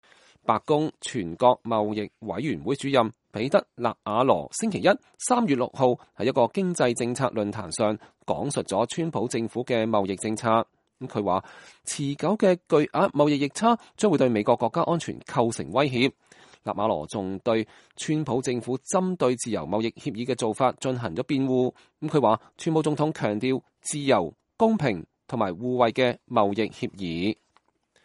納瓦羅在全國商業經濟協會(National Association for Business Economics)舉辦的經濟政策會議上作主題演講時強調，巨額而且持久的貿易逆差是個至關重要的問題。